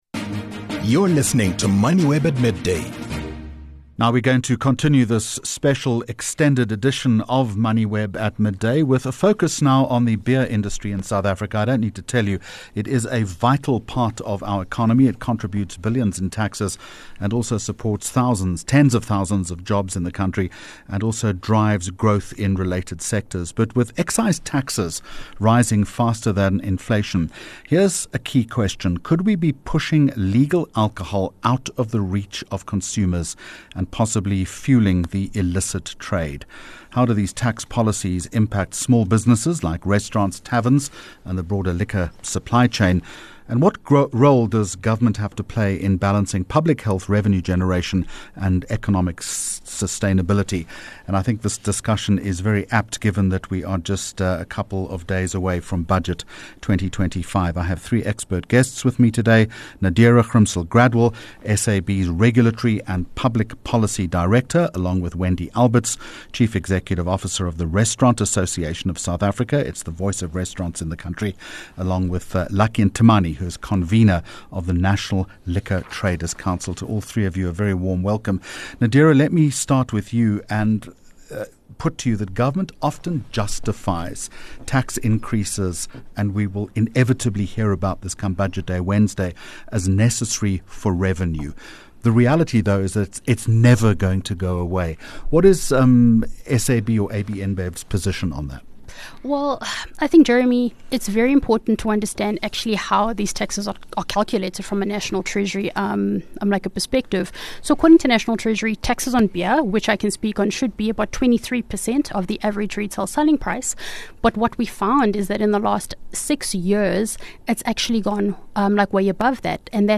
Latest Special ReportsThese podcasts range from an in-depth interviews with business leaders, as well as the analysis of the news of the day, comprehensive analyses of news events to inspirational interviews with business leaders.